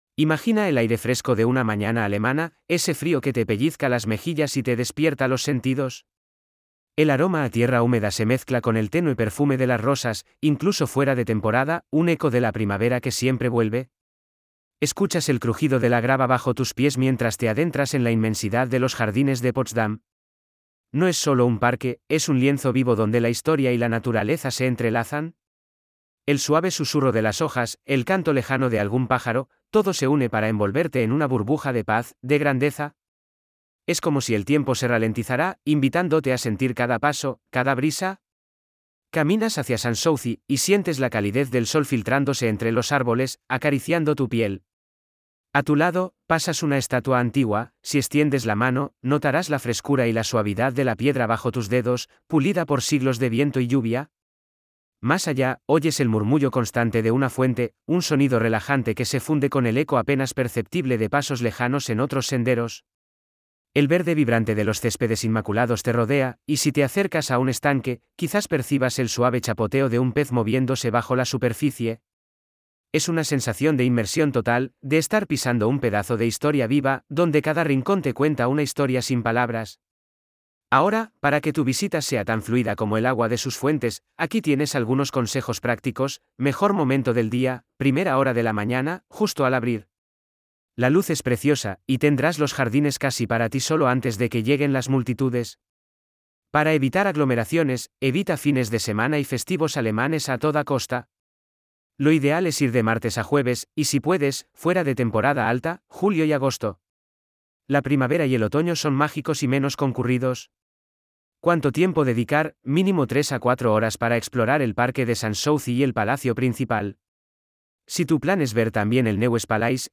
🎧 Guías de audio disponibles (2) Guía de Experiencia Emocional (ES) 🔗 Abrir en una nueva pestaña Información práctica (ES) 🔗 Abrir en una nueva pestaña